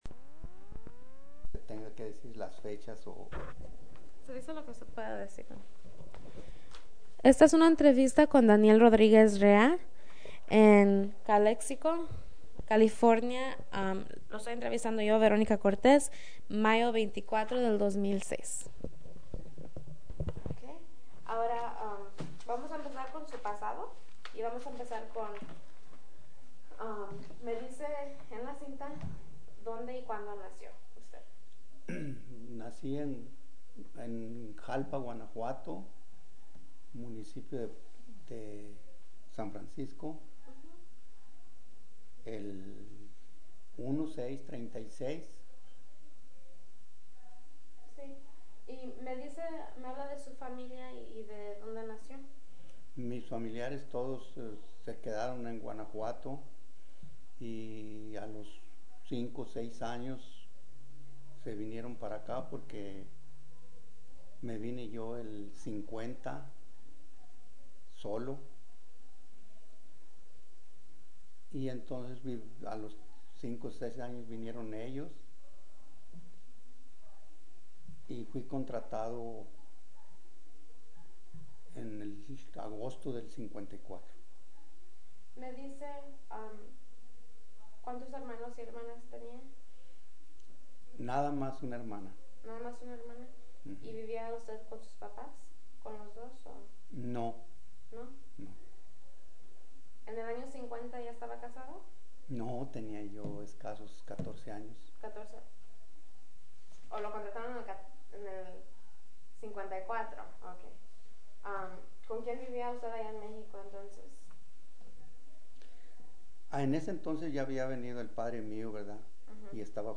Original Format Mini disc